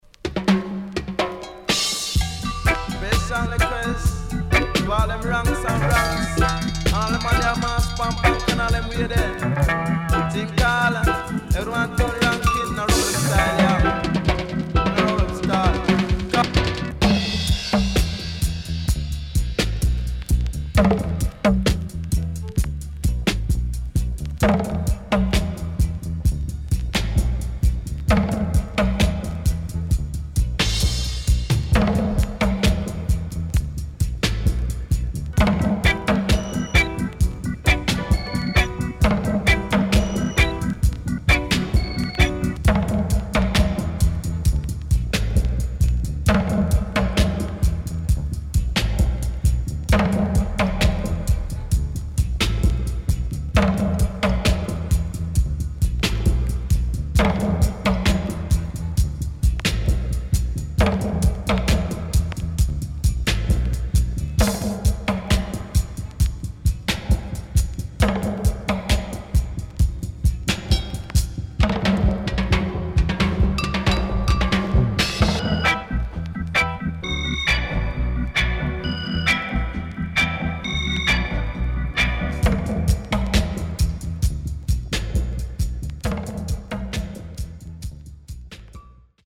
CONDITION SIDE A:VG(OK)
SIDE A:所々チリノイズがあり、少しプチパチノイズ入ります。